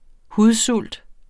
Udtale [ ˈhuðˌsulˀd ]